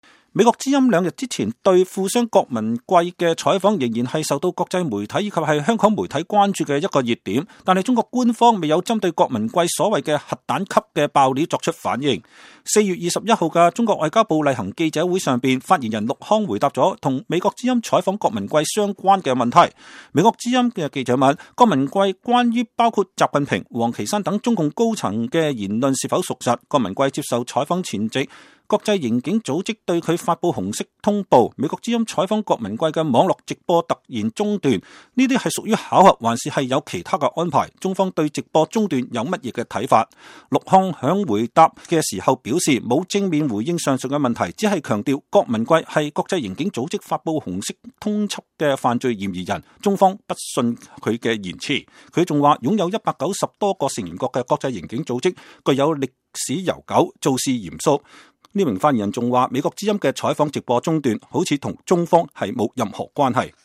4月21日的中國外交部例行記者會上，發言人陸慷回答了與美國之音採訪郭文貴相關的提問。